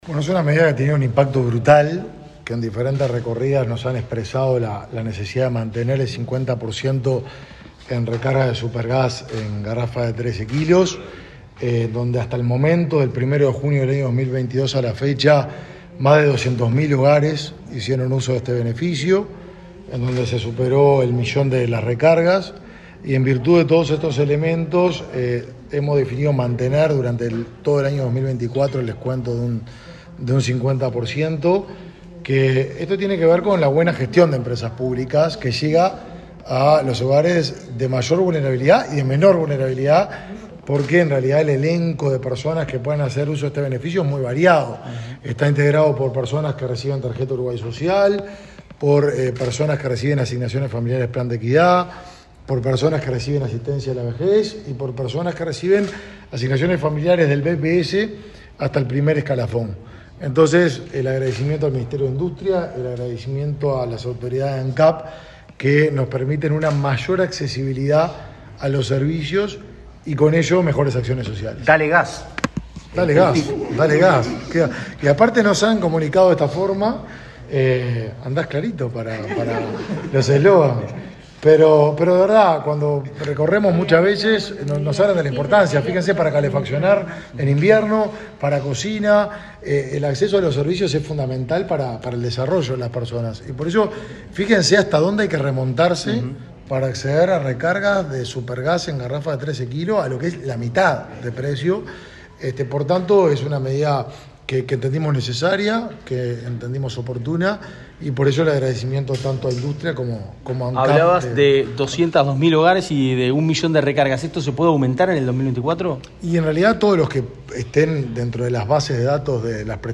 Declaraciones de autoridades en el Mides
Declaraciones de autoridades en el Mides 26/12/2023 Compartir Facebook X Copiar enlace WhatsApp LinkedIn El titular del Ministerio de Desarrollo Social (Mides), Martín Lema; el vicepresidente de Ancap, Diego Durand, y la ministra de Industria, Elisa Facio, informaron a la prensa sobre la extensión de la bonificación del 50% en las recargas de garrafas de supergás para beneficiarios de programas del Mides. Luego los tres jerarcas dialogaron con la prensa.